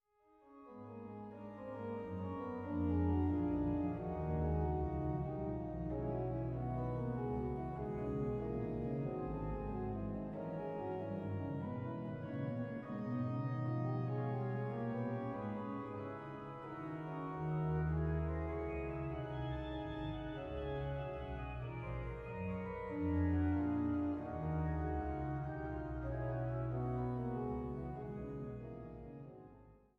Woehl-Orgel in der Thomaskirche zu Leipzig